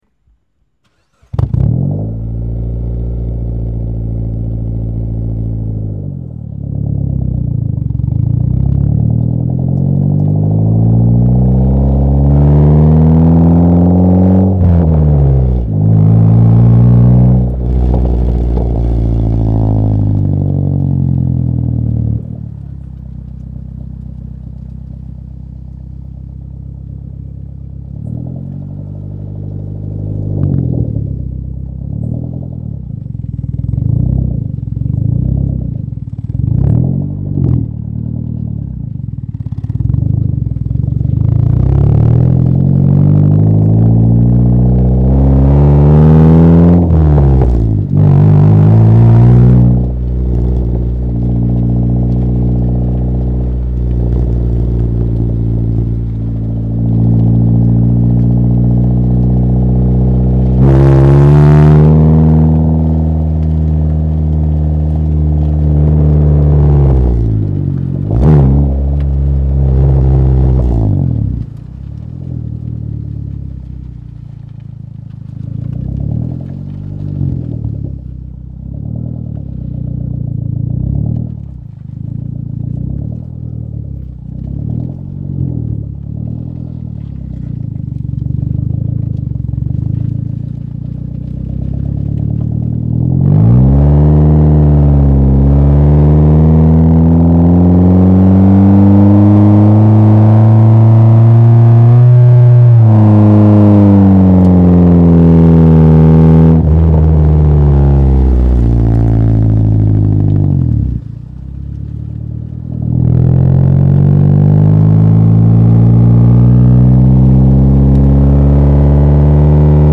Звуки гоночных машин
Звук двигателя Mitsubishi Lancer